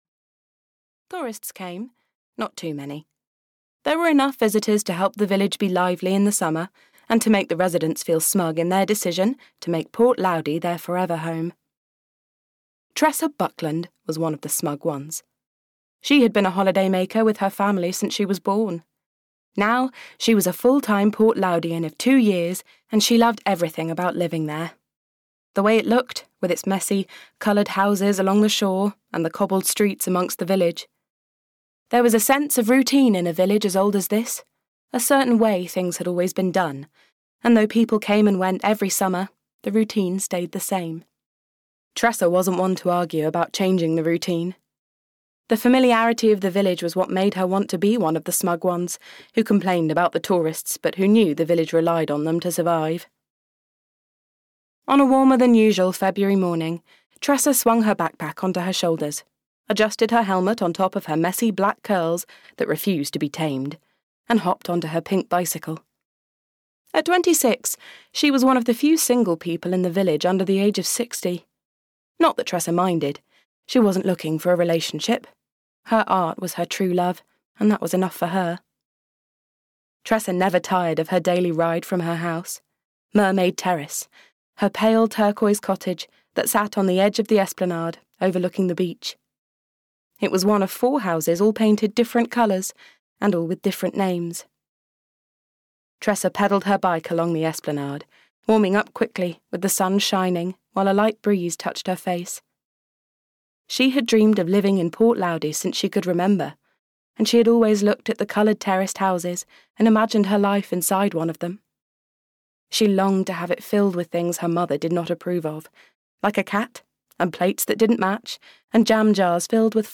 Audio knihaFinding Love at Mermaid Terrace (EN)
Ukázka z knihy